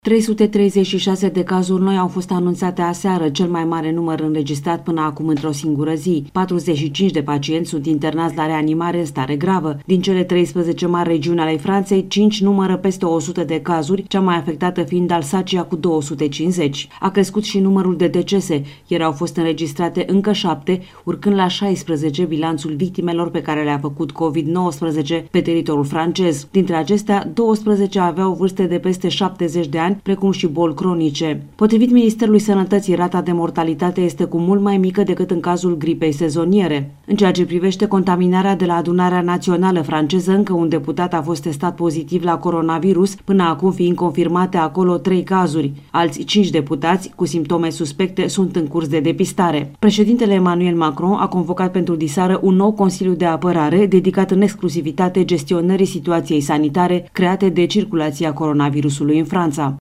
după cum transmite de la Paris